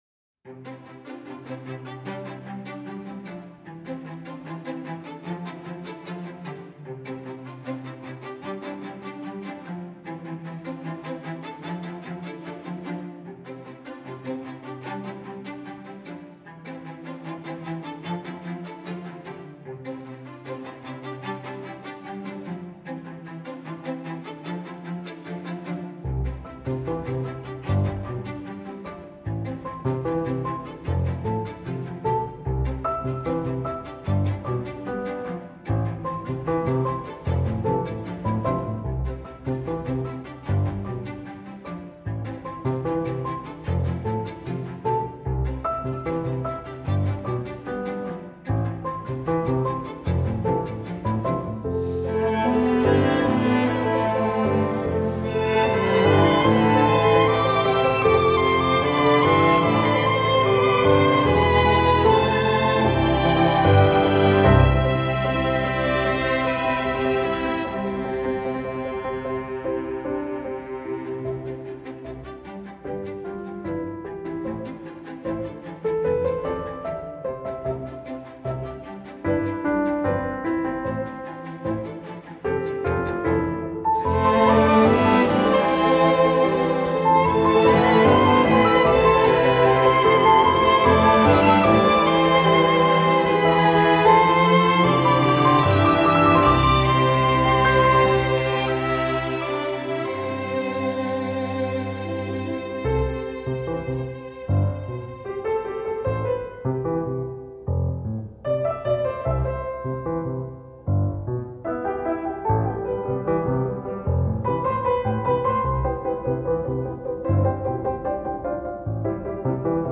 سبک ارکسترال , موسیقی بی کلام